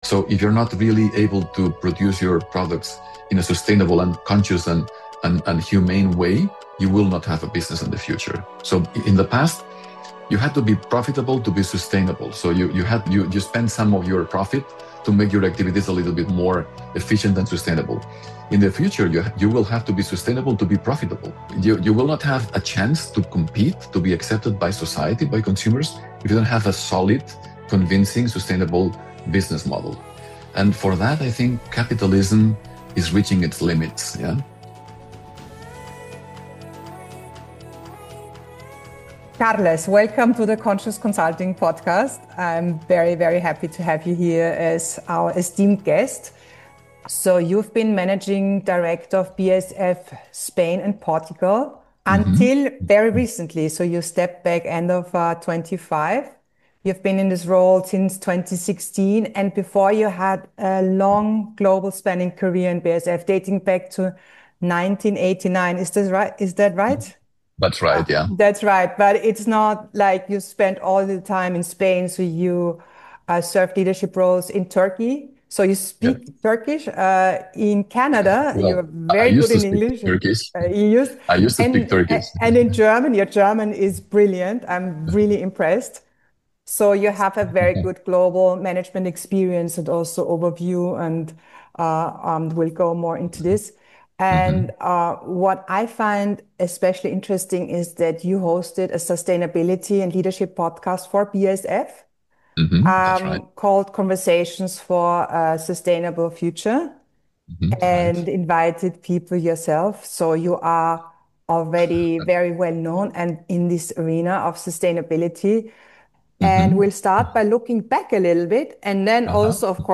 A rare, honest conversation about leadership, responsibility, and the courage to act in alignment with what one says and stands for.